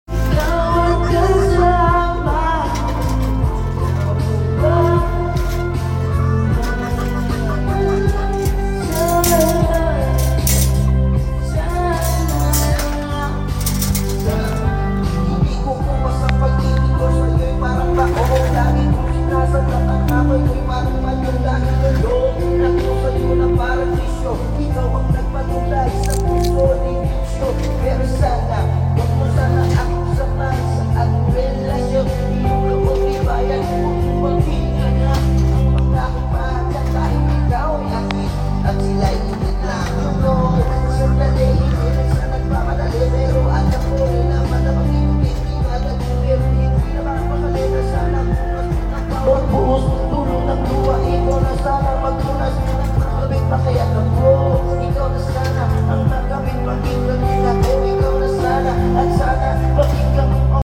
Rabbit Sound Effects Free Download